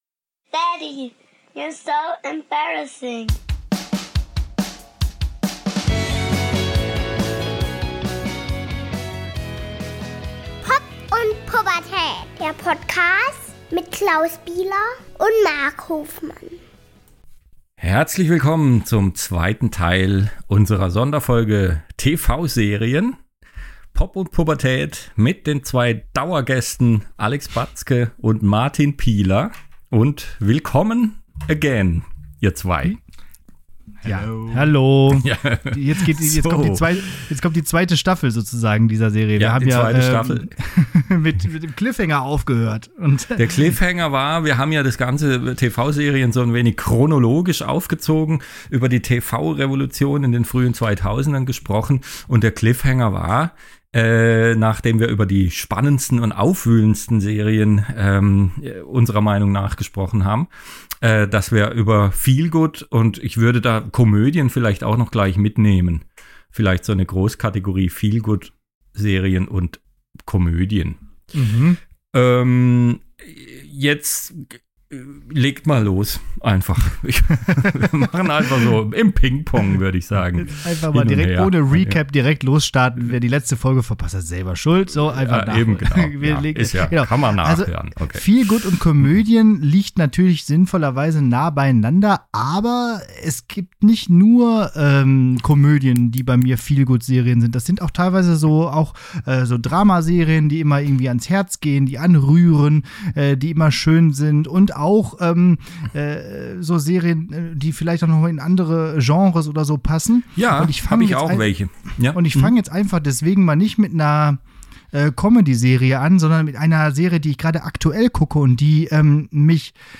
Feelgood, Hype und Dystopien Die drei Studienräte sprechen weiterhin über Serien.